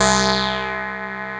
DOBLEAD C3-R.wav